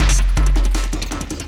53 LOOP 05-L.wav